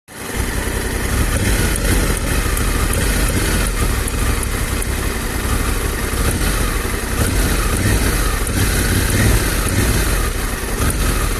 Und es klickt nur aus der Nulllage auf den ersten paar mm Drehbewegung.
Das klicken kommt nicht aus dem Gasgriff sondern aus Richtung dem Display.
Habe euch eine Audio Aufnahme gemacht, während ich ein paar mm am Gas spiele.
Es klingt wie ein kleiner Mikroschalter und es kommt aus dem Display.